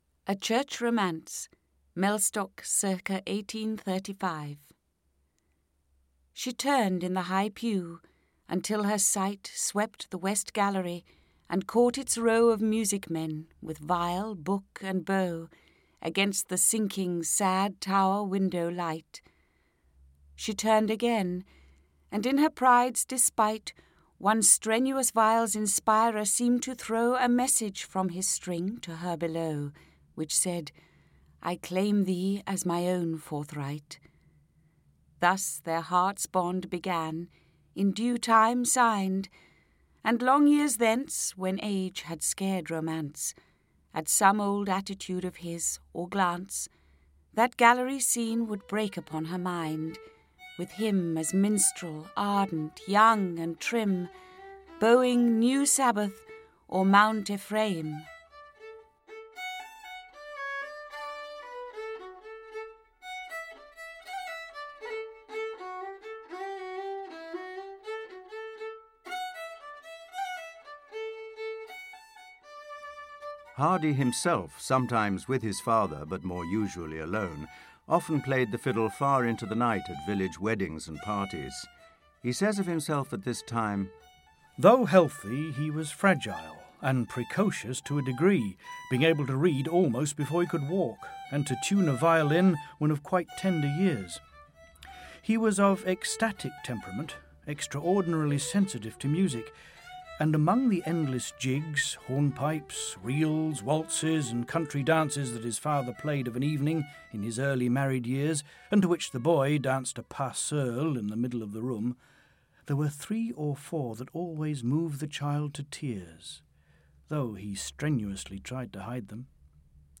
Winter Words (EN) audiokniha
Ukázka z knihy